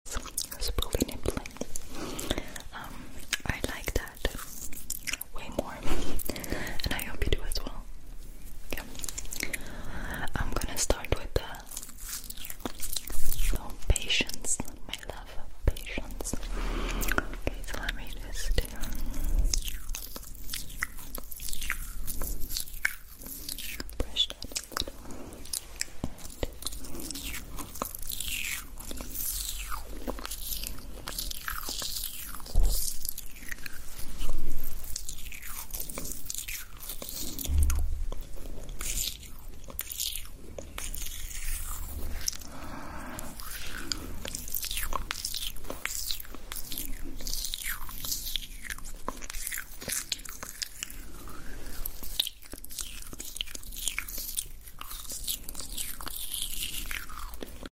Spoolie nibbling (intense mouth sounds) sound effects free download